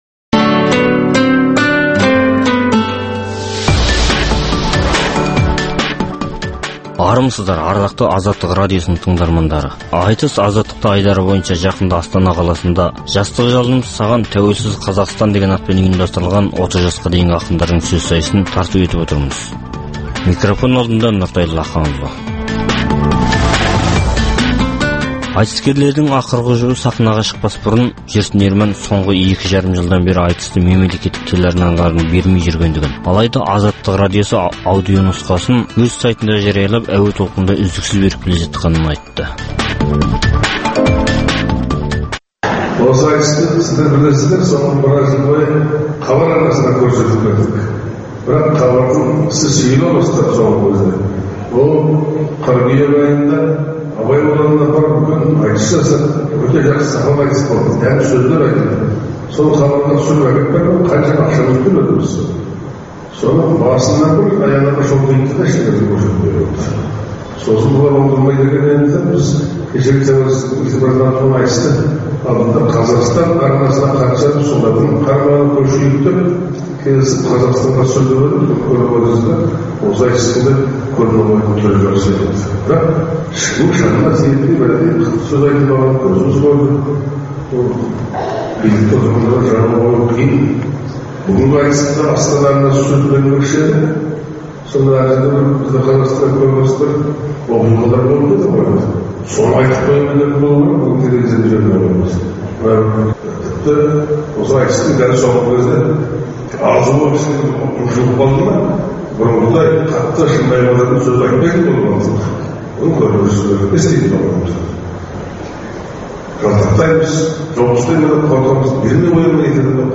Қазақстанда әр уақытта өткізілетін ақындар айтысының толық нұсқасын ұсынамыз.